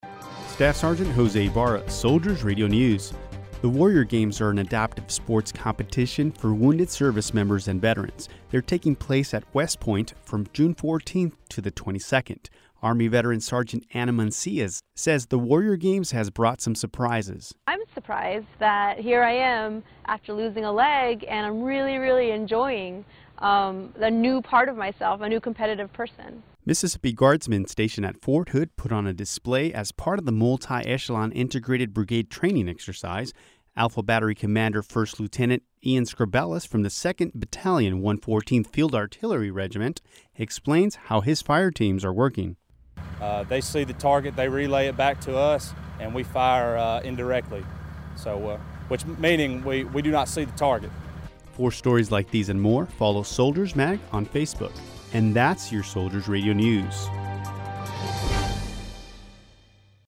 Soldiers Radio News